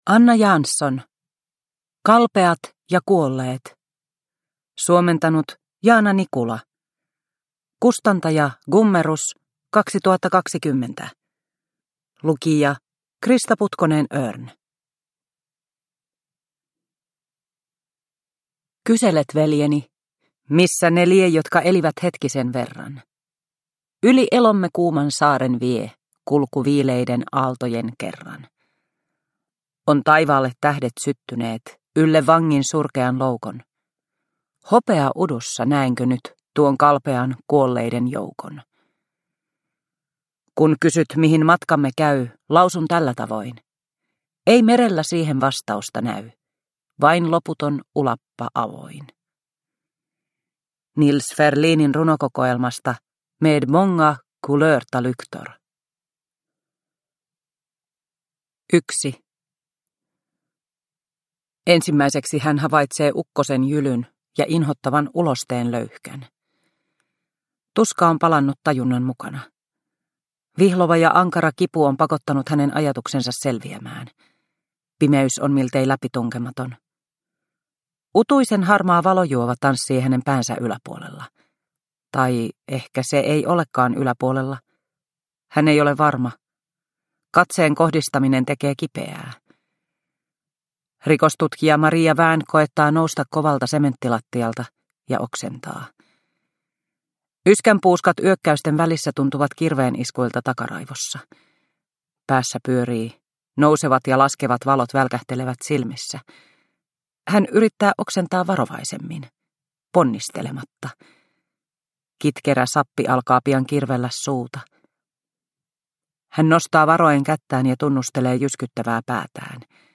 Kalpeat ja kuolleet – Ljudbok – Laddas ner